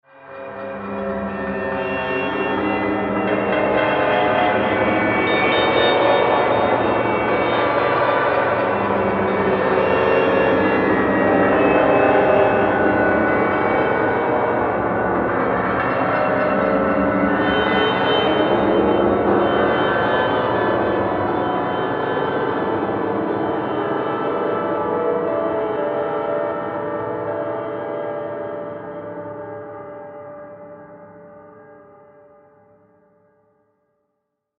Chaotic-scary-background-sound-effect.mp3